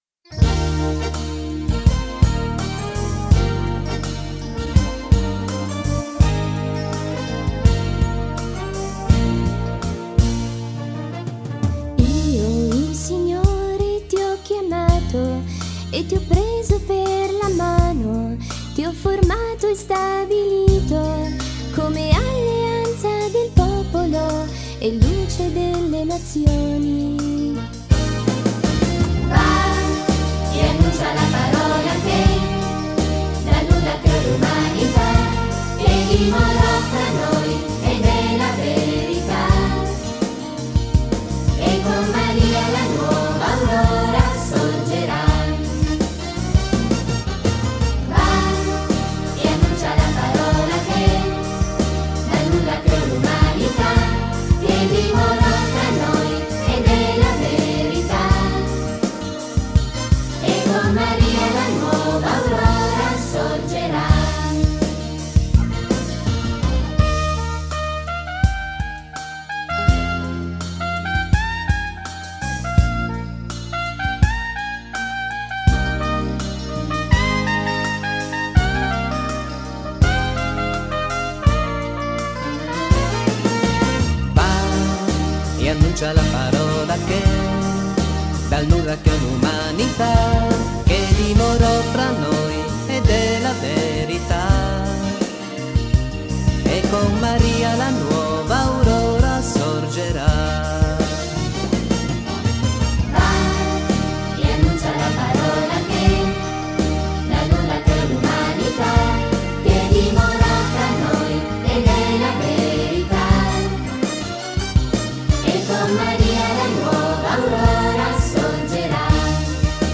Canto per la Decina di Rosario e Parola di Dio: Io il Signore ti ho chiamato